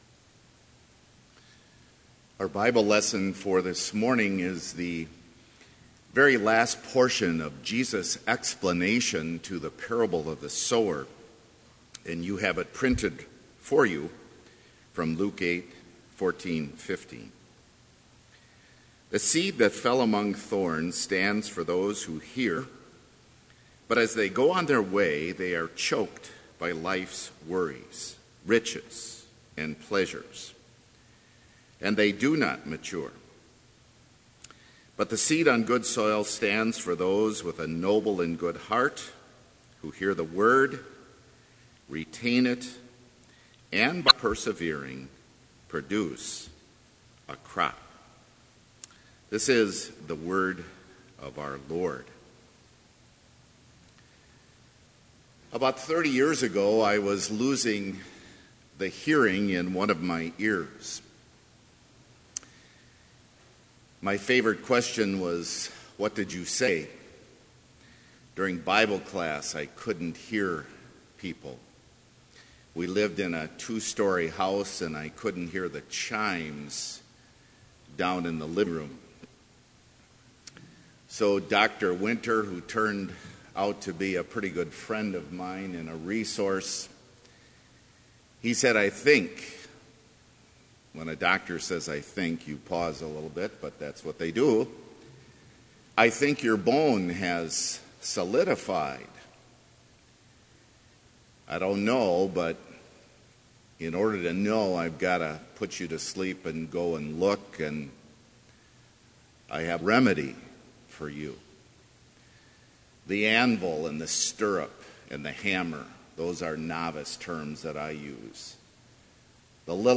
Complete service audio for Chapel - February 1, 2016